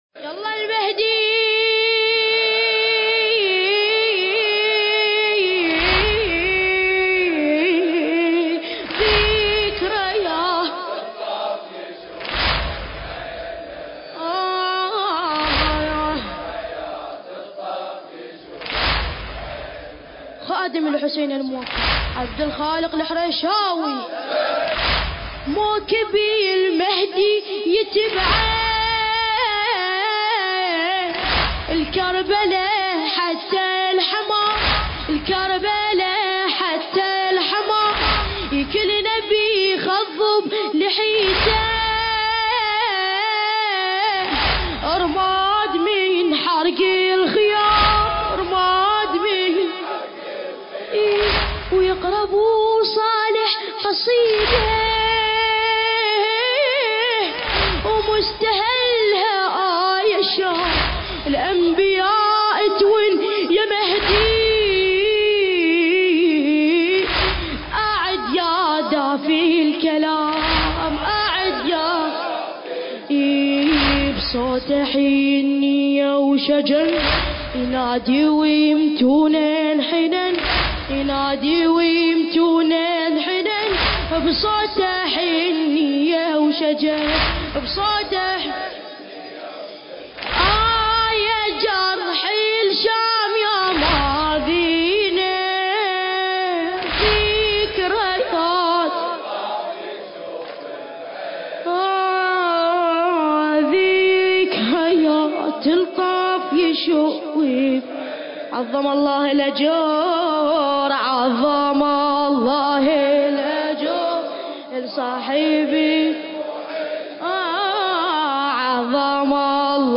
زيارة الأربعين ليلة ١٥ صفر ١٤٣٩ هـ كربلاء المقدسة فندق كرستال